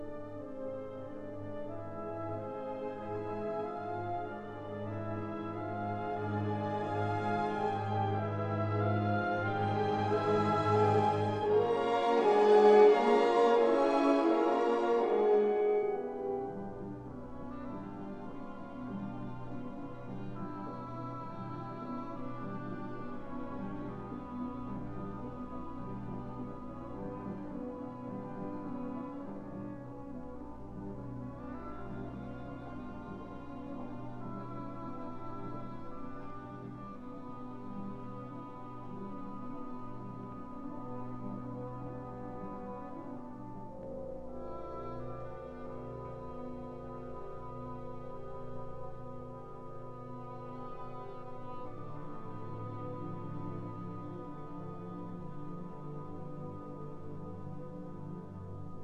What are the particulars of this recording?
1958 stereo recording